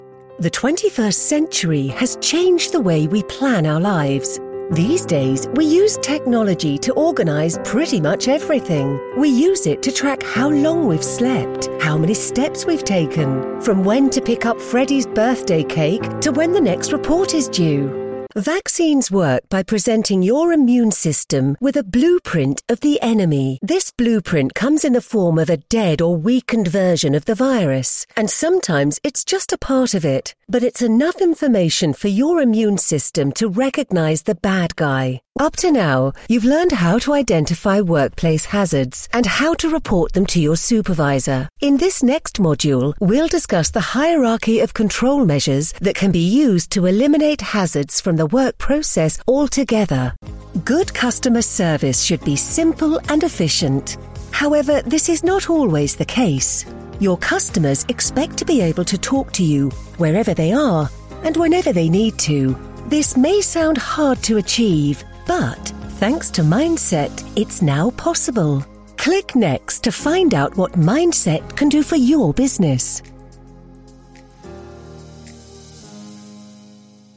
Female
British English (Native)
Assured, Authoritative, Character, Corporate, Engaging, Friendly, Gravitas, Natural, Reassuring, Smooth, Warm, Versatile
Narrative demo reel 2025_01.mp3
Microphone: Neumann TLM 103
Audio equipment: Sound proof booth Scarlett 2i2 interface